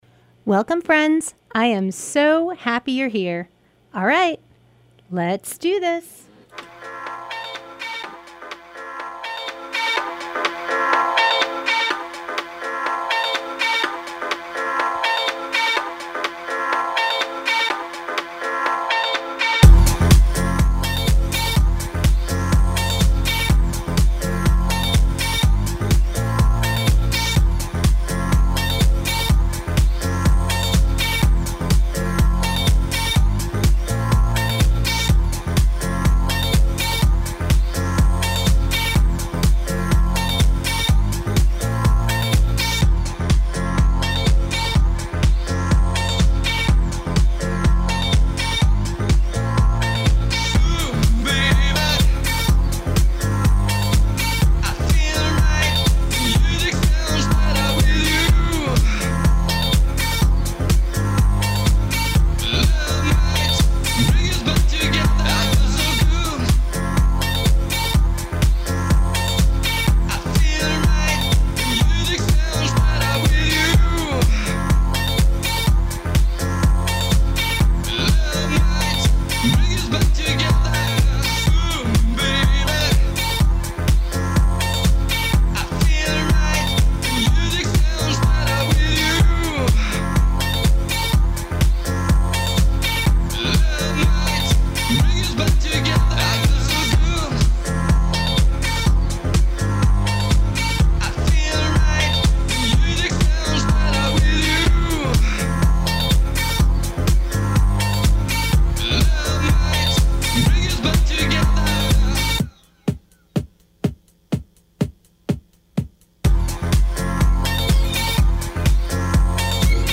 Broadcast live every Thursday night from 6:30 to 8pm on WTBR